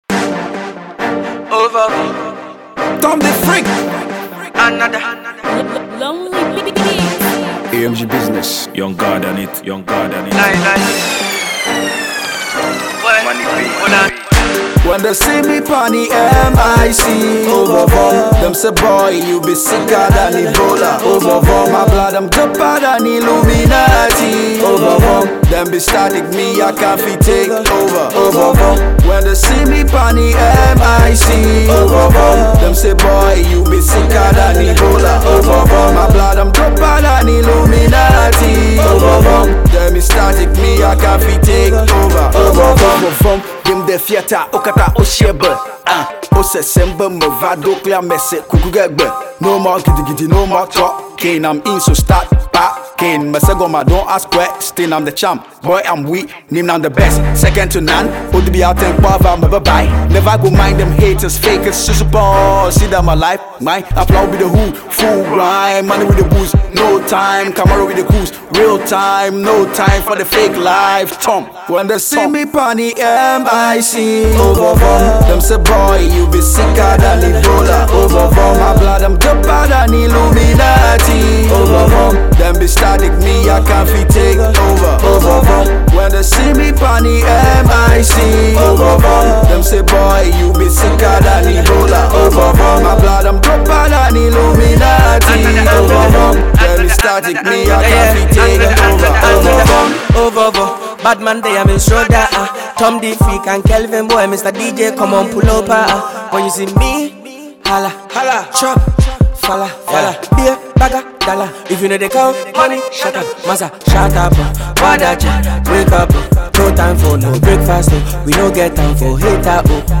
Budding Hip-hop artiste